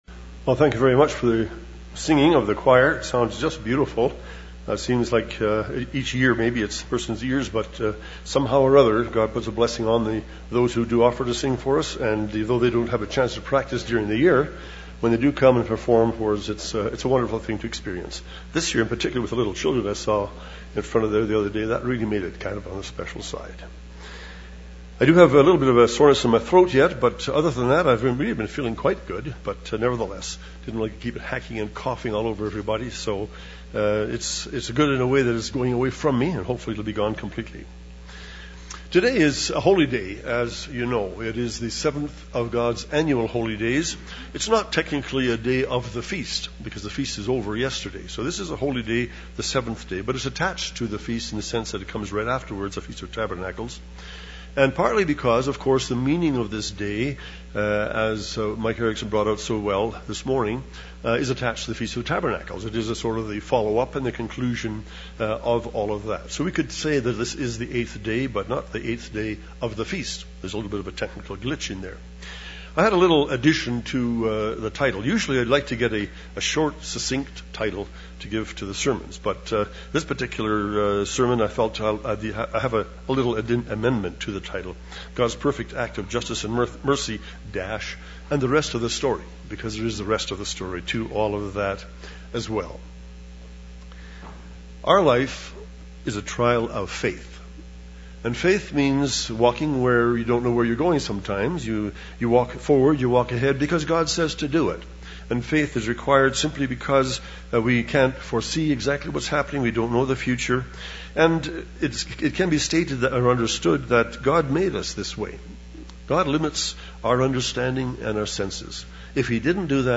This sermon was given at the Canmore, Alberta 2011 Feast site.